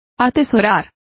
Complete with pronunciation of the translation of hoard.